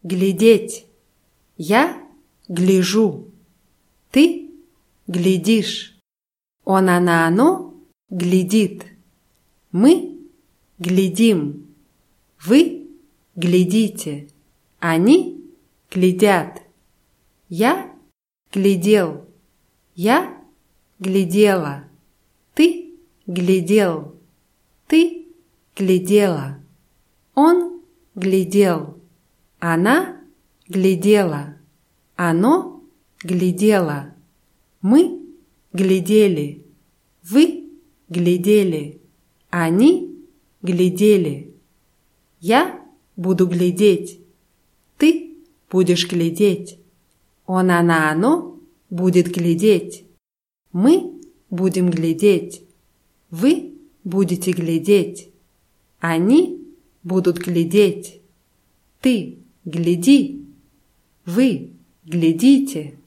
глядеть [glʲidʲétʲ]